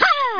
1 channel
SND_JUMP1.mp3